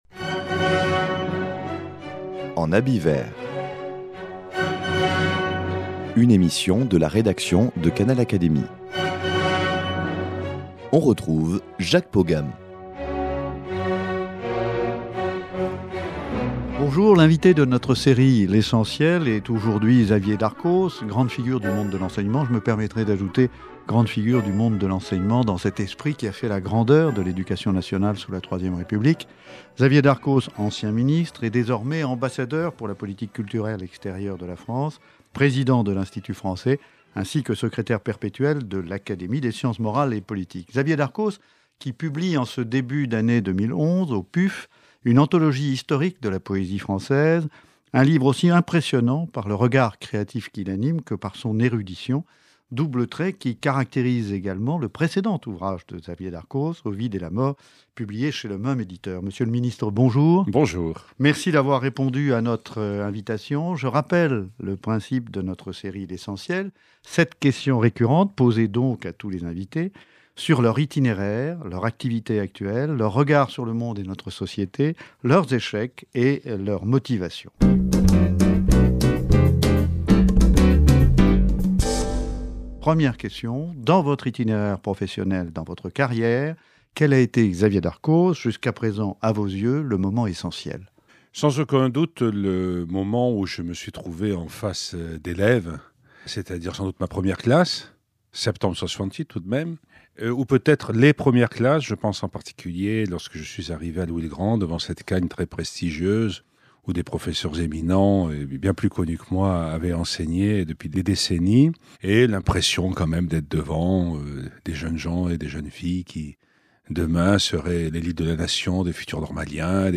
L’invité de notre série « L’essentiel » est aujourd’hui Xavier Darcos - grande figure du monde de l’enseignement dans cet esprit qui fit la grandeur de l’Éducation nationale sous la IIIe République.